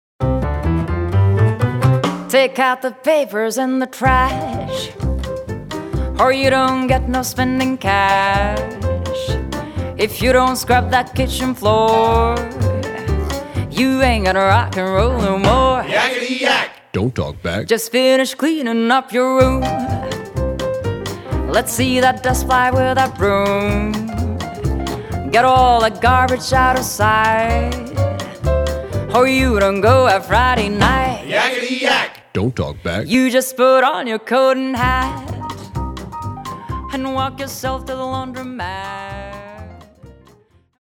Vocalist
Grand Piano
Double-Bass
Drums
Guitar
Violin
Trombone